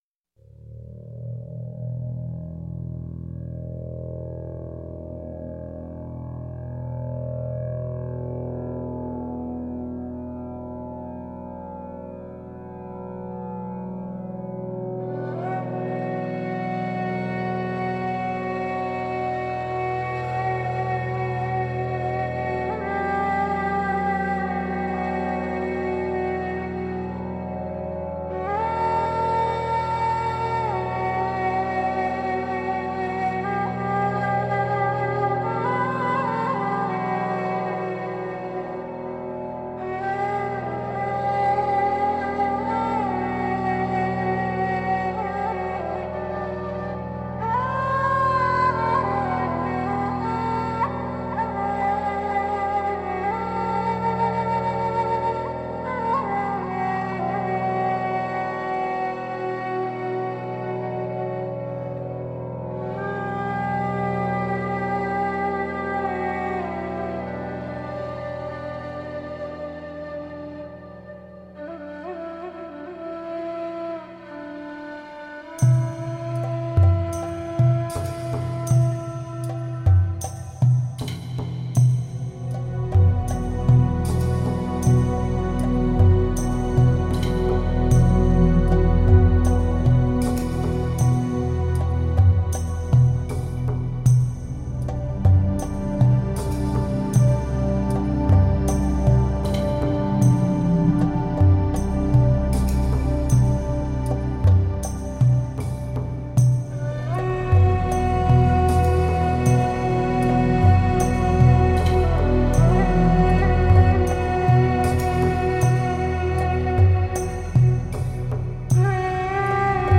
Beautiful ambient space music.
Tagged as: Ambient, New Age, Space Music